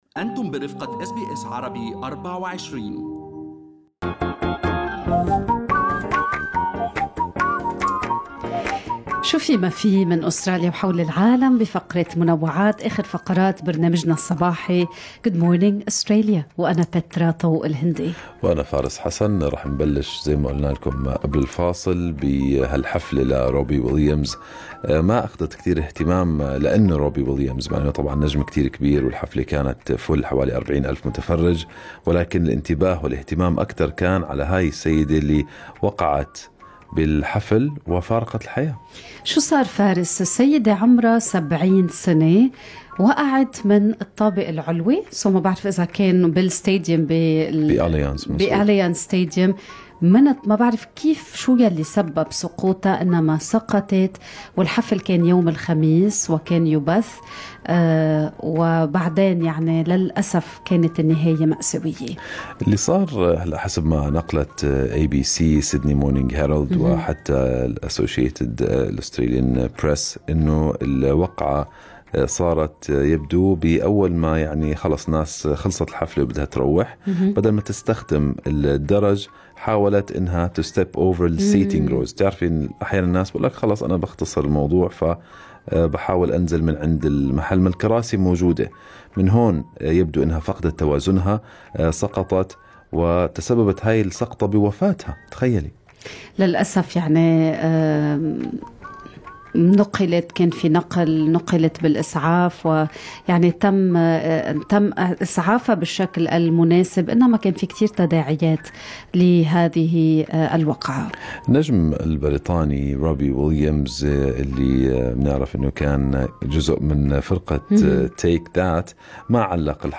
نقدم لكم فقرة المنوعات من برنامج Good Morning Australia التي تحمل إليكم بعض الأخبار والمواضيع الخفيفة.